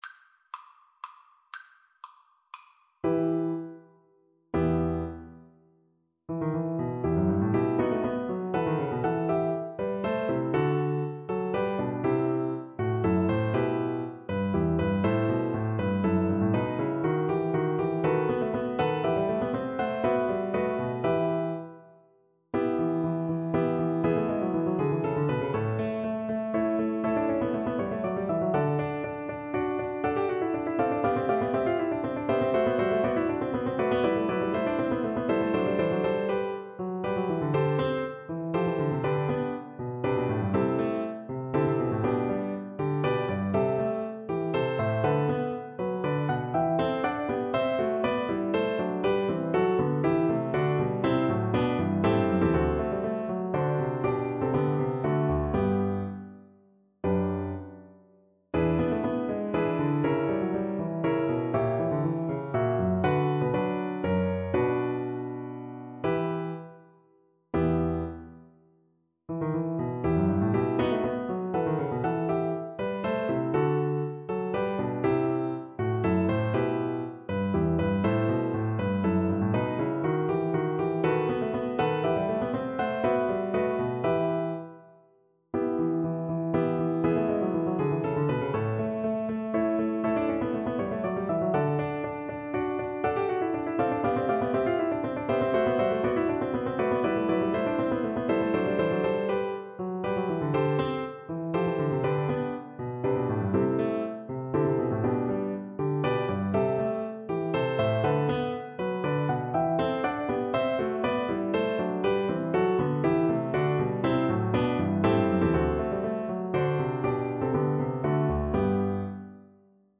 FlutePiano
3/4 (View more 3/4 Music)
D5-E7
Allegro (View more music marked Allegro)
Flute  (View more Intermediate Flute Music)
Classical (View more Classical Flute Music)